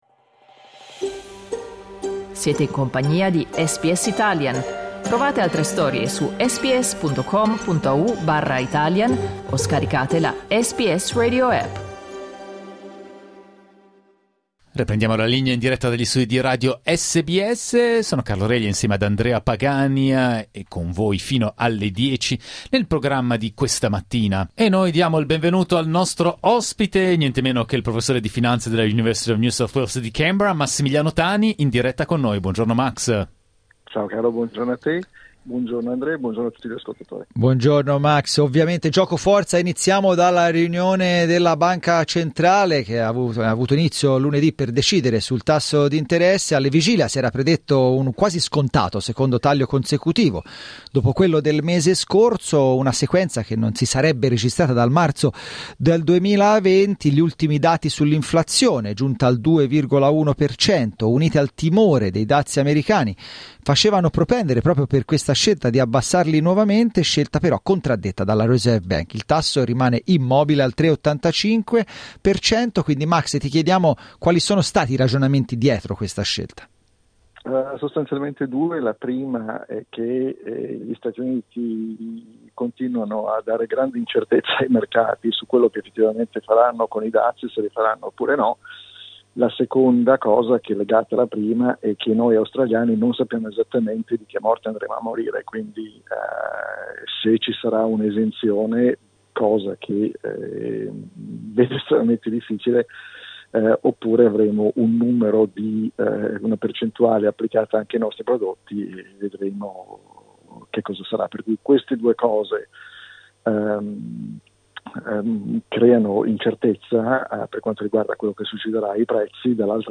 Ascolta l'analisi del professore di Finanza